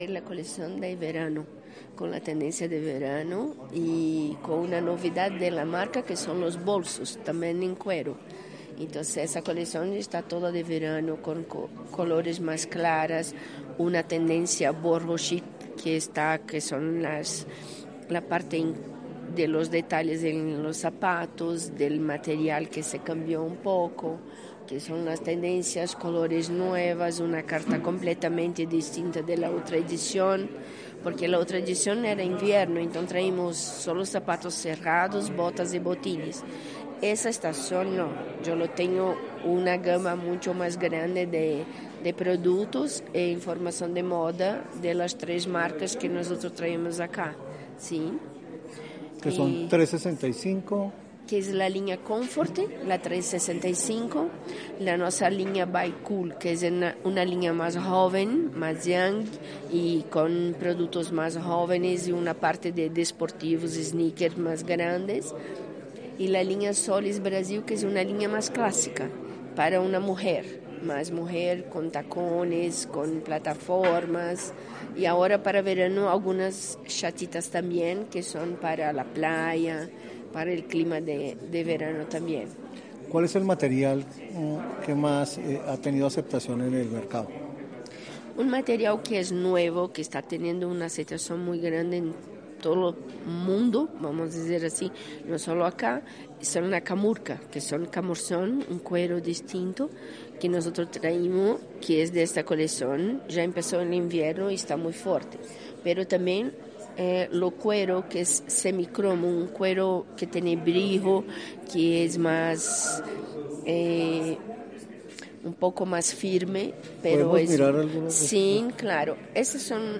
photocall-Hotel-Cosmos-Calzado-de-Brasil.mp3